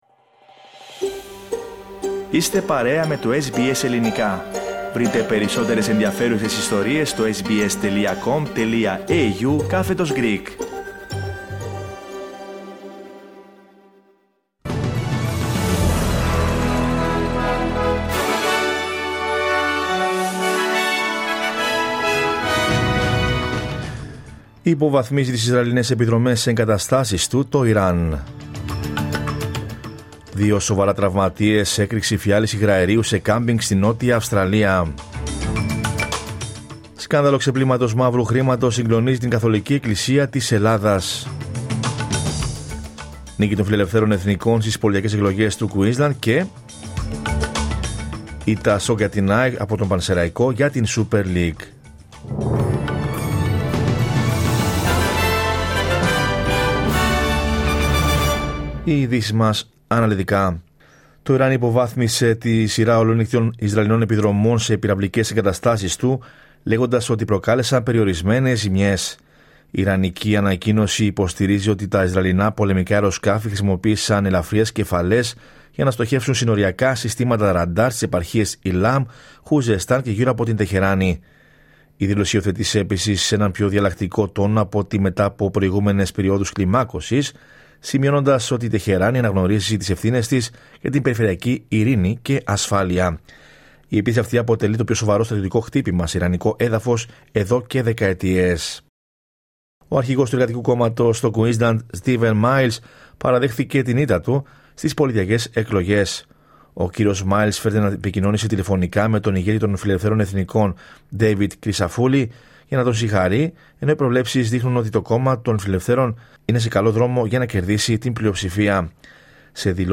Δελτίο Ειδήσεων Κυριακή 27 Οκτωβρίου 2024